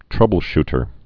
(trŭbəl-shtər)